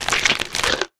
sounds / mob / camel / eat5.ogg
eat5.ogg